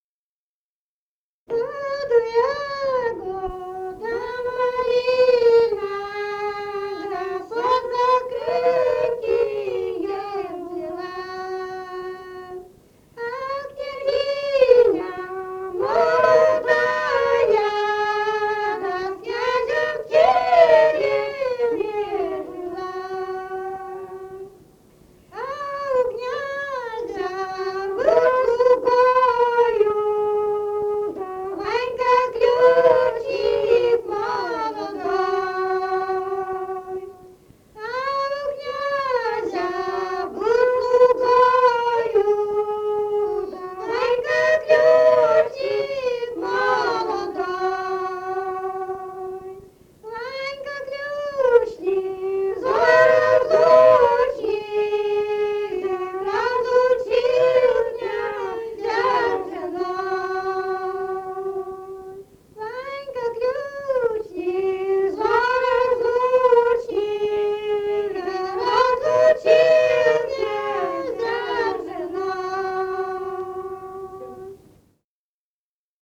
«В саду ягода-малина» (лирическая) — ст.